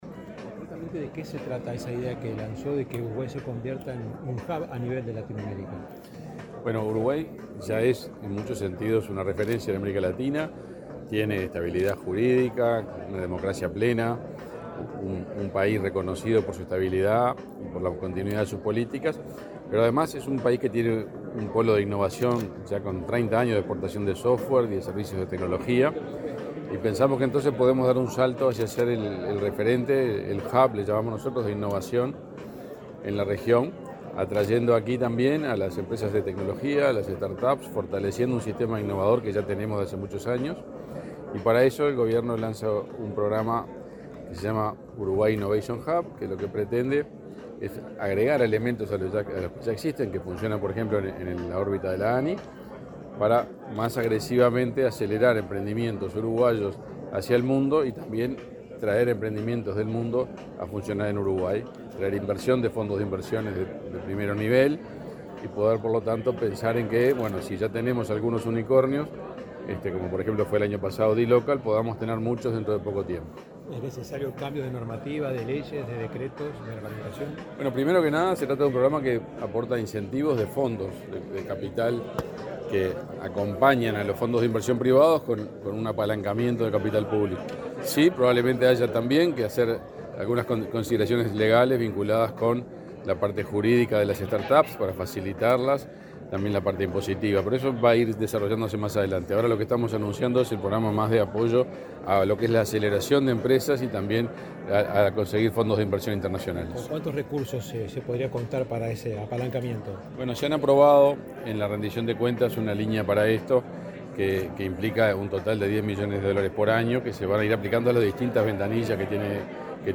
Declaraciones del ministro de Industria, Omar Paganini
El ministro de Industria, Energía y Minería, Omar Paganini, participó en Punta del Este del foro Test & Invest Uruguay Business Summit y, luego,